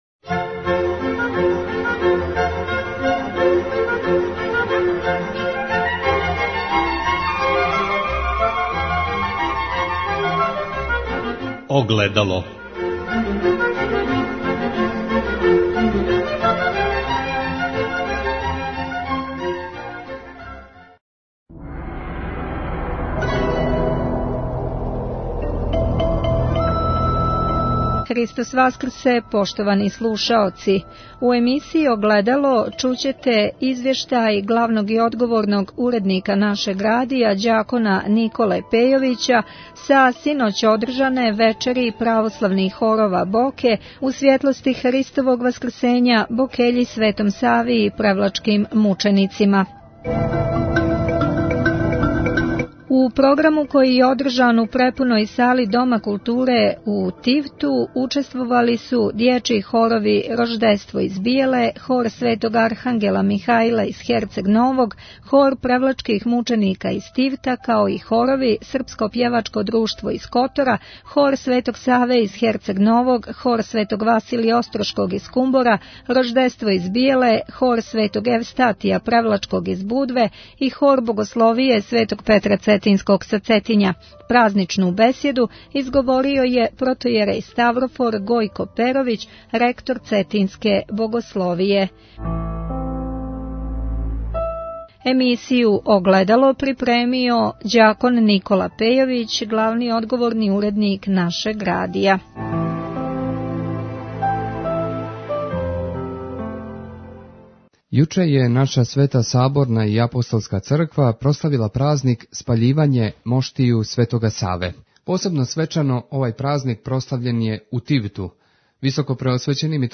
У Тивту је одржано вече православних хорова Боке
У четвртак 10. маја у препуној сали Дома културе у Тивту одржано је вече православних хорова Боке " У свјетлости Христовог Васкрсења- Бокељи Светом Сави и Светим превлачким мученицима". У програму су учествовали дјечји хорови